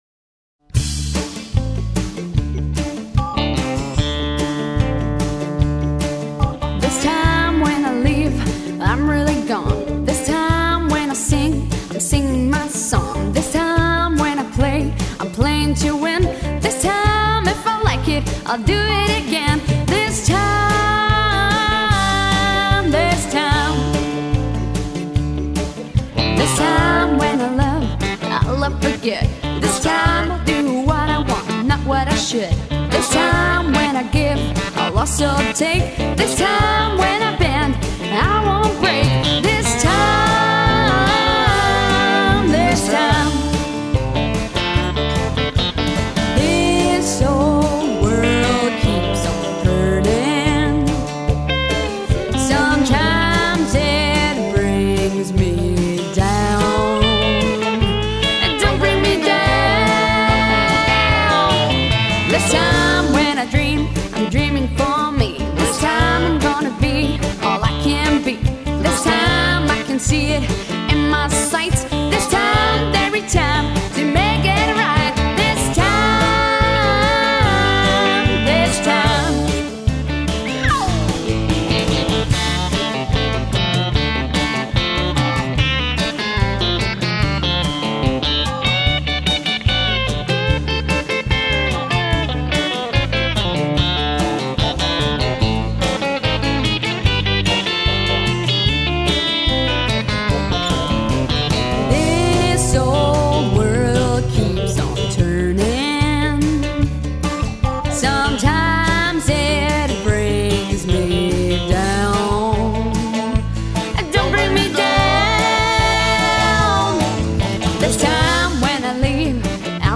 Country / rock.